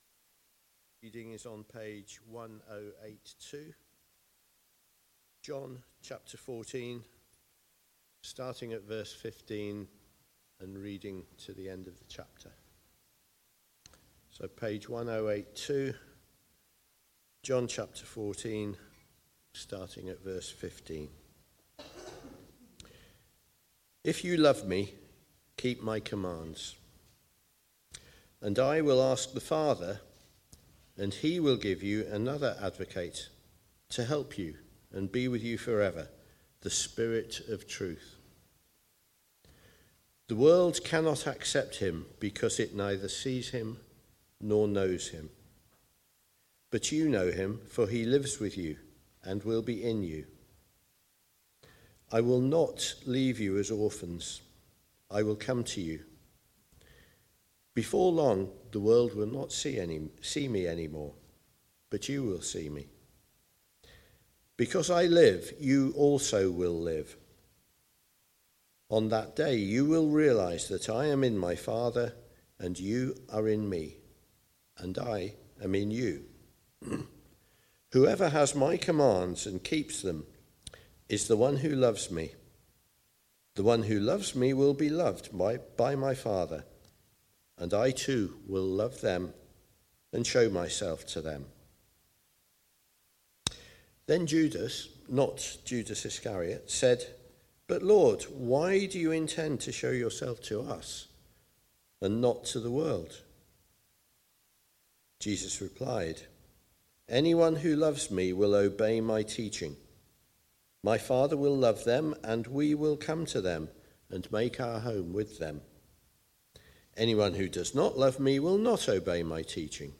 The Gift (John 14:15-31) from the series Comfort and Joy - John 13-17. Recorded at Woodstock Road Baptist Church on 09 February 2025.